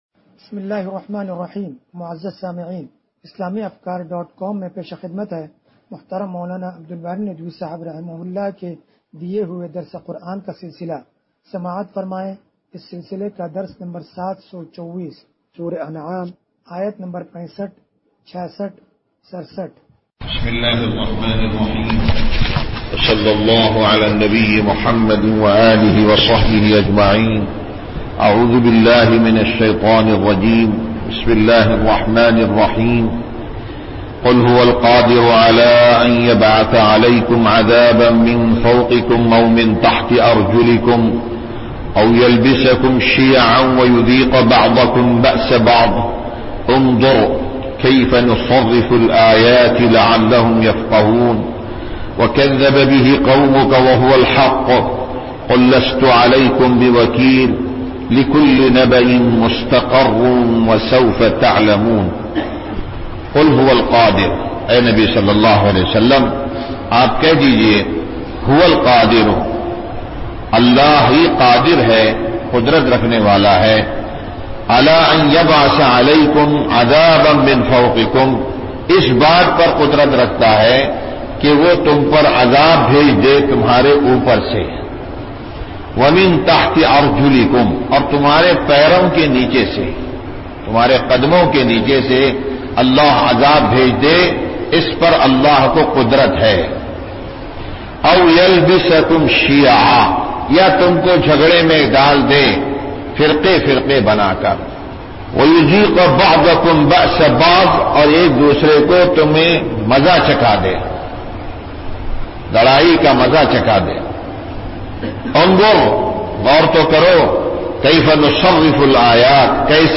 درس قرآن نمبر 0724